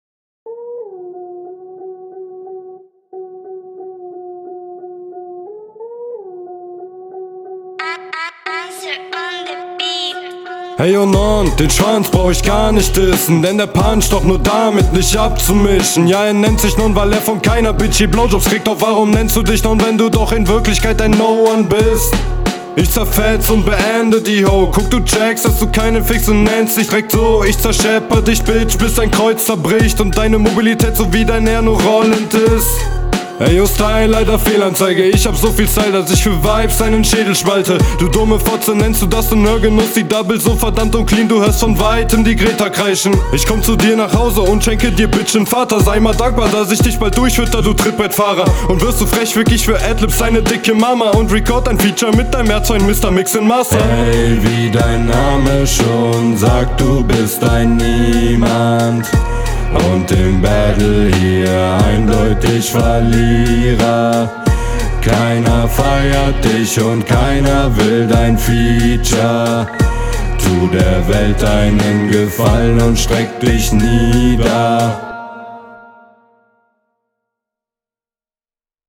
Flow gut, Mix auch, Punches meh, Reime gut
Danke für die autotune part parodie.
Gute Reimtechnik, stabil gerappt, Inhalt geht klar